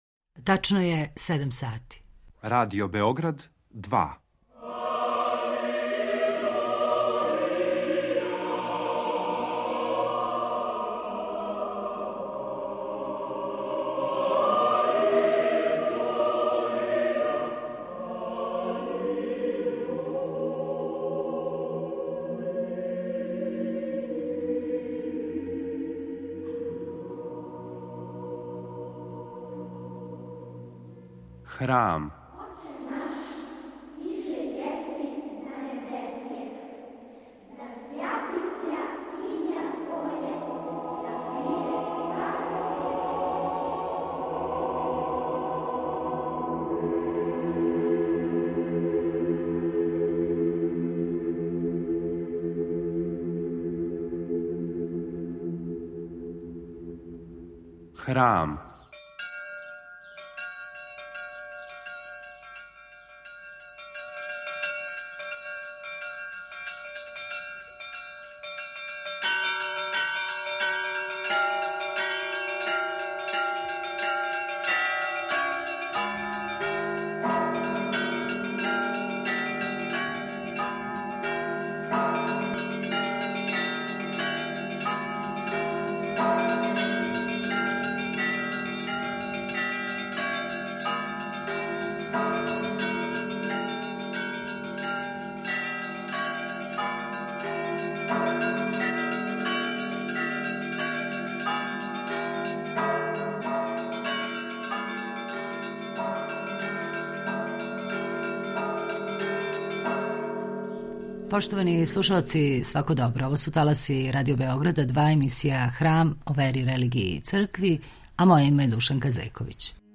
Забележено у САНУ, на научној трибини у поводу 800 година од посвећења Светог Саве за првог српског архиепископа.